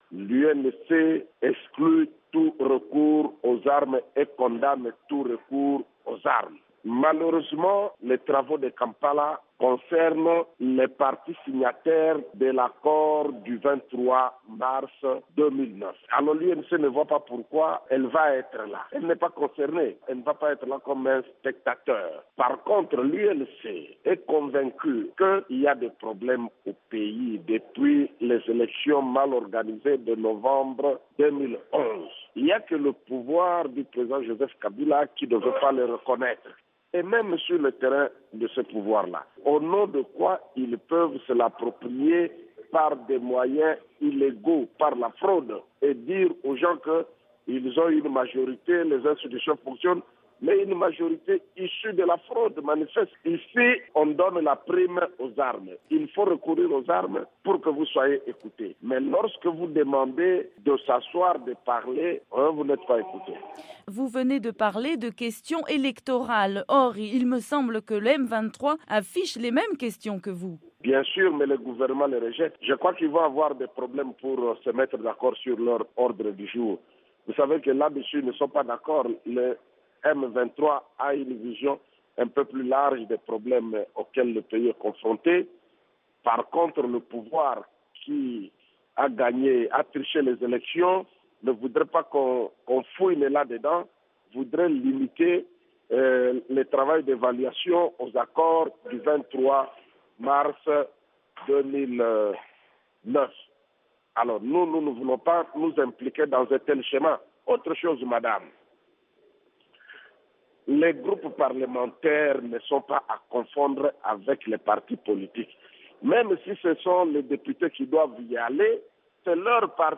le député de l'opposition Jean Baudouin Mayo Mambeke